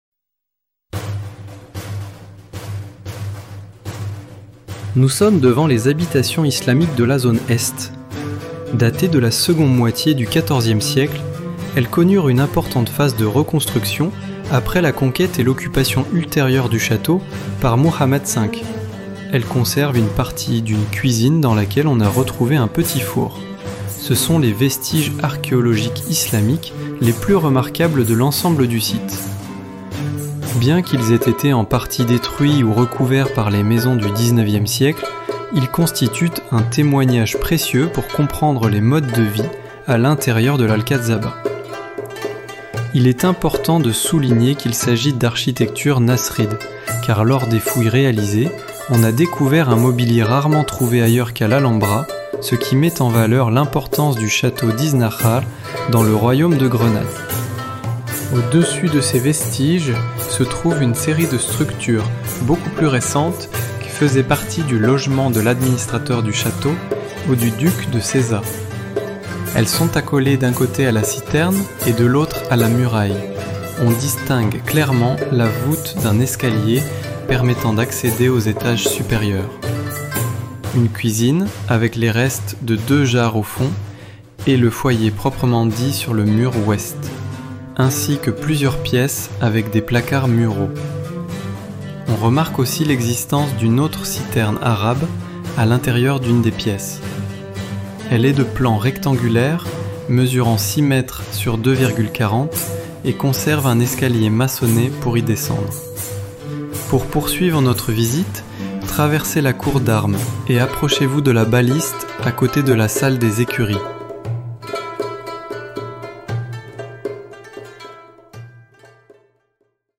Ruta audioguiada
audioguia-frances-qr9.mp3